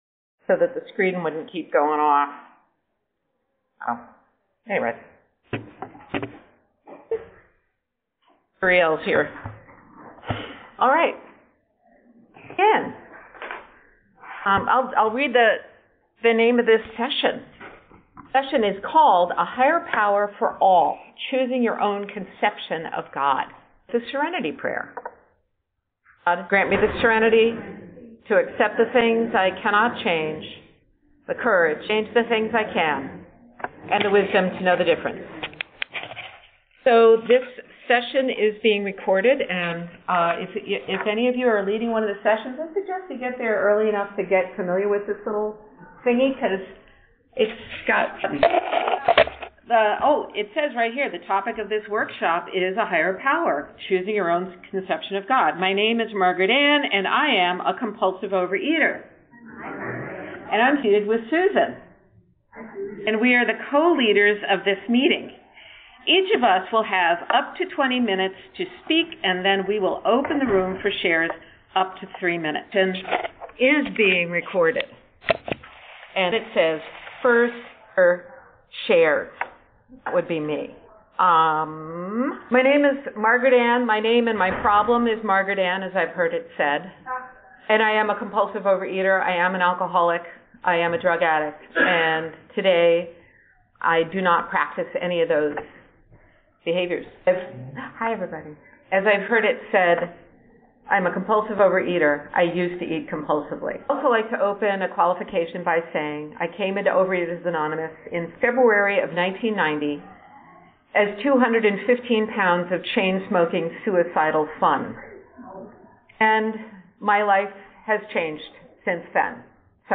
A workshop given at the 2024 OA Region 6 convention, held in October in Nashua, NH, US.